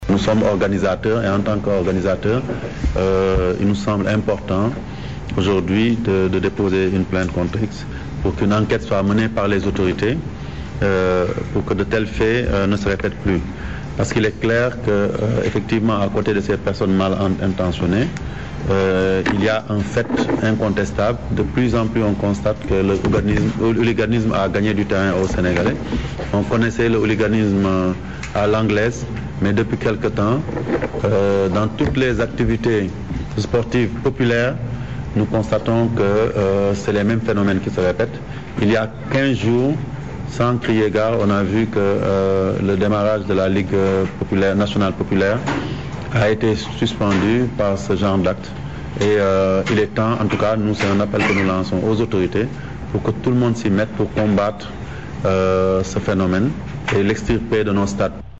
Me Augustin Senghor est interrogé par nos confrères de la Rfm.